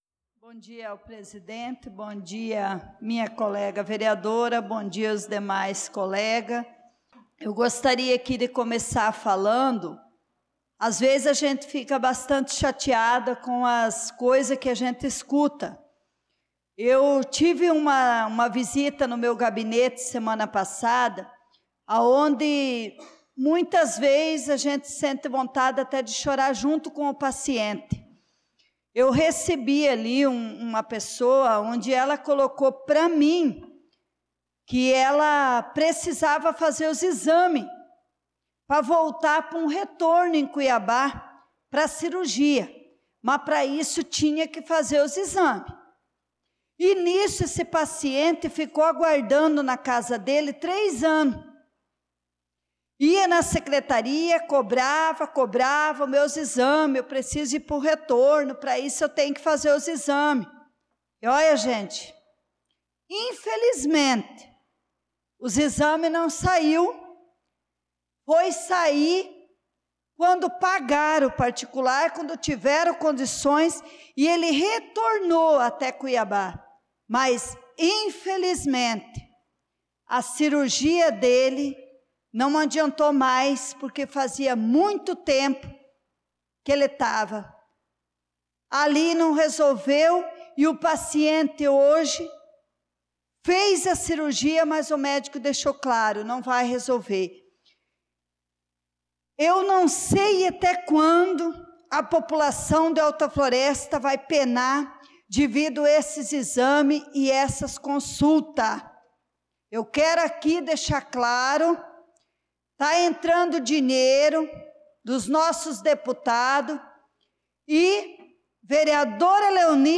Pronunciamento da vereadora Leonice Klaus na Sessão Ordinária do dia 09/06/2025